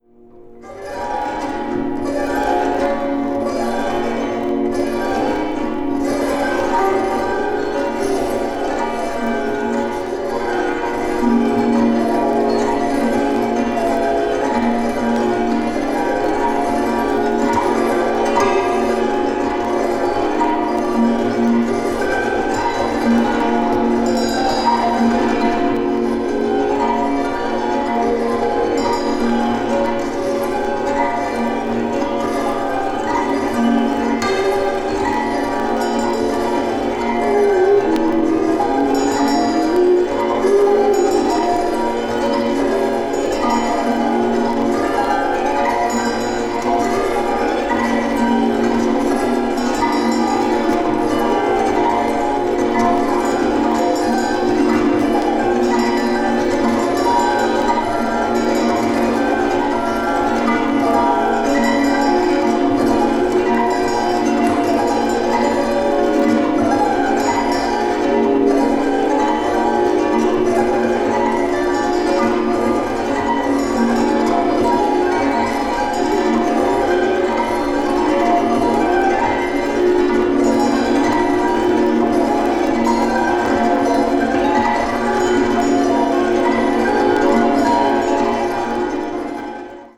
avant-jazz   ethnic jazz   experimental jazz   free jazz